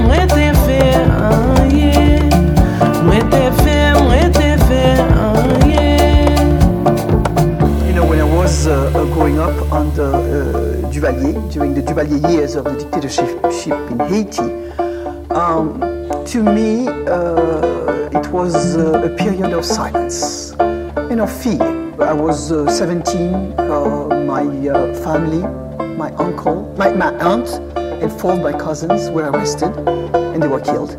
Blues ; Musique du Monde